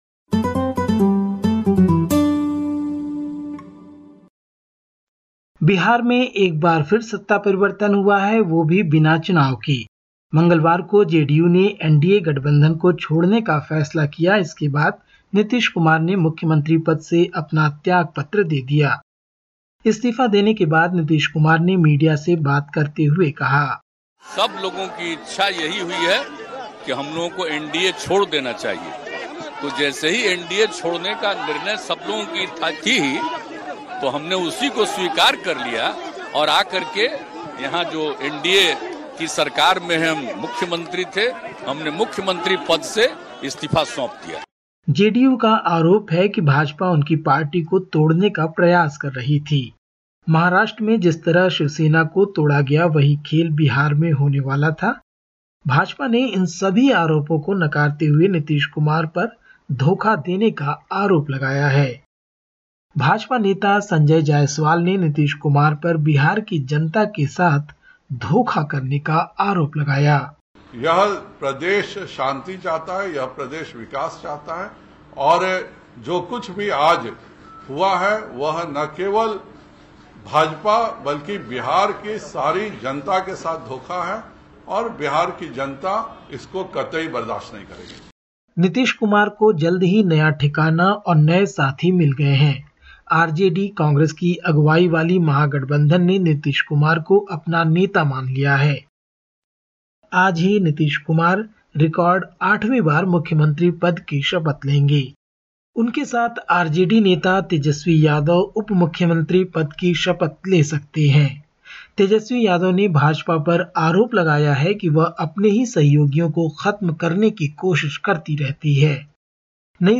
Listen to the latest SBS Hindi report from India. 10/08/2022